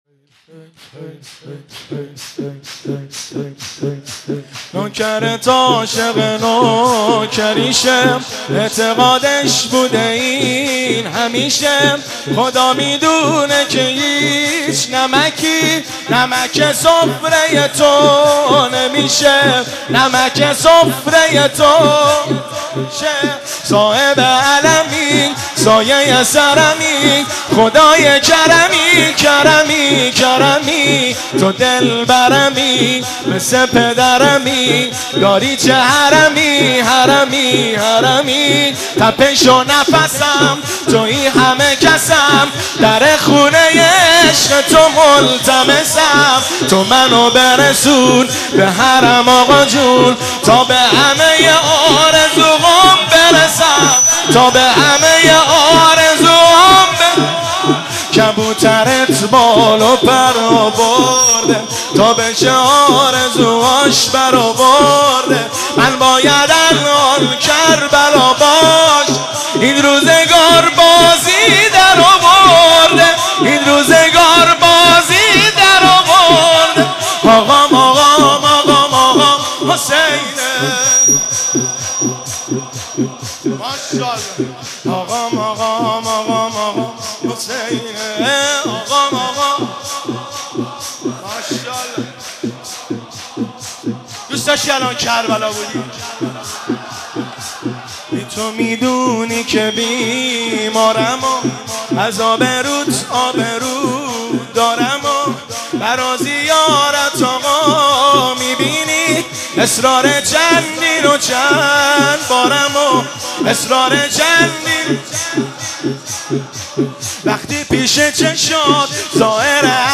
مناجات